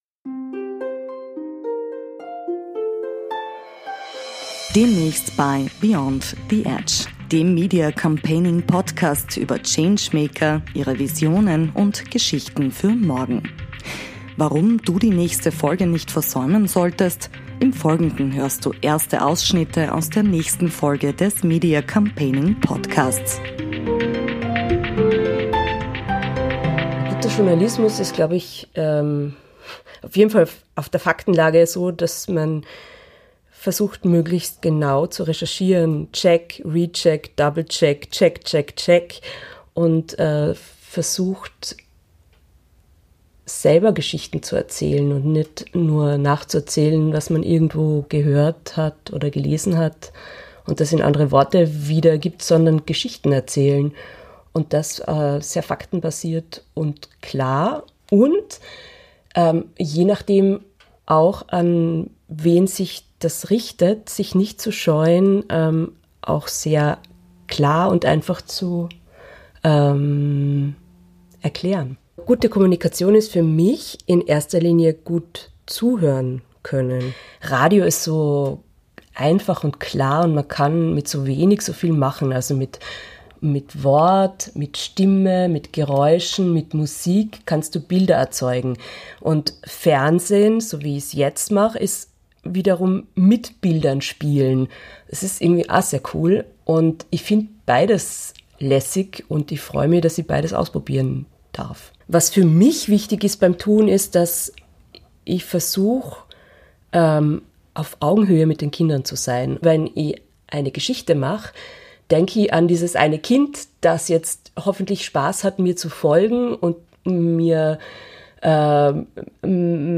Erste Ausschnitte aus einem inspirierenden Interview über Kommunikation, Kinder und die Zukunft